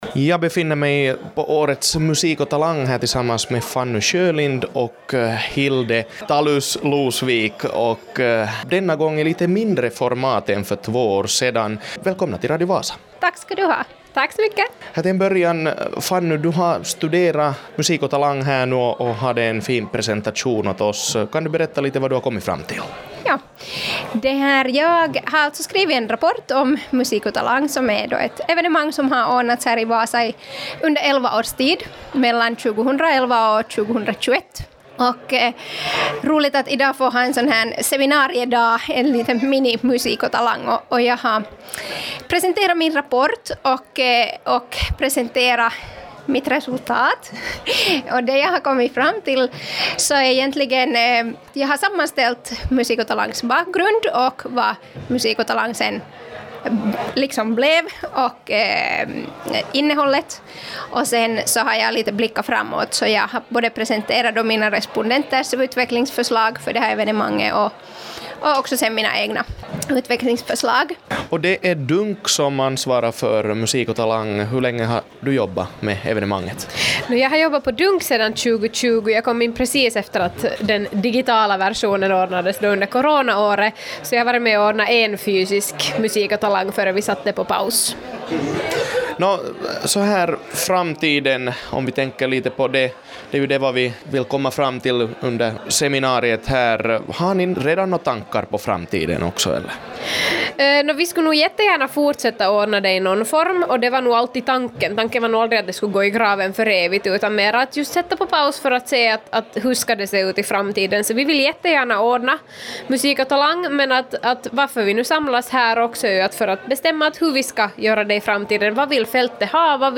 Musik & Talang ordnades i mindre format i Vasa efter en paus på ett år. Evenemanget vill ta en ny riktning i framtiden och det diskuterades mycket under årets seminarie.